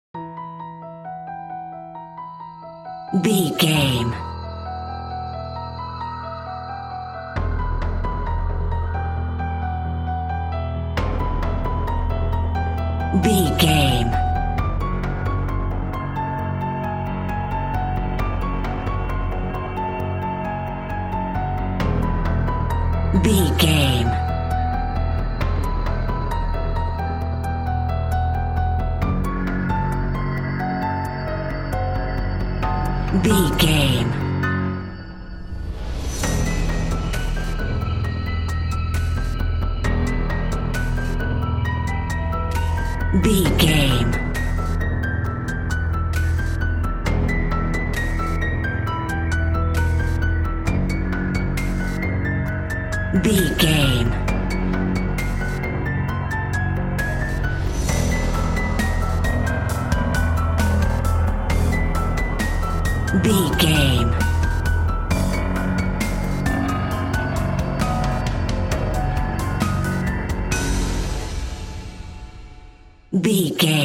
Aeolian/Minor
chaotic
eerie
haunting
piano
synthesiser
drum machine